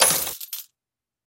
одну медальку кинули в сундук с медалями